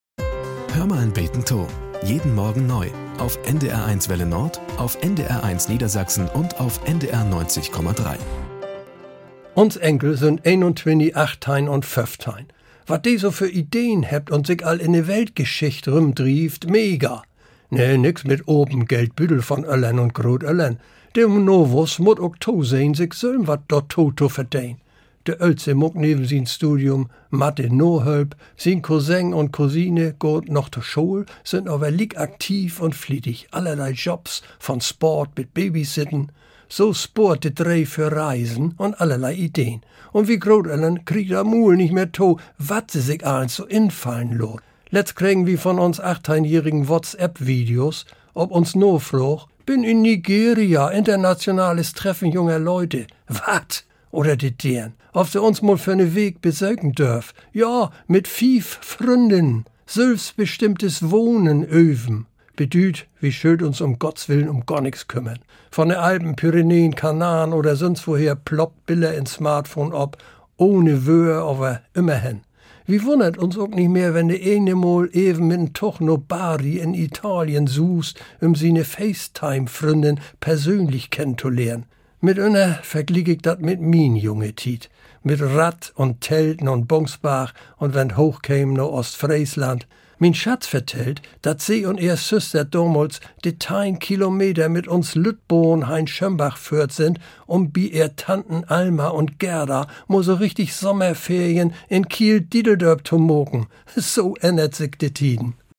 Nachrichten - 23.06.2023